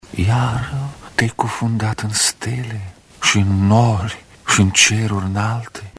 În cadrul aceluiași eveniment, organizat de Radio România și Institutul Cultural Român, actorul Ion Caramitru va recita din lirica lui Mihai Eminescu: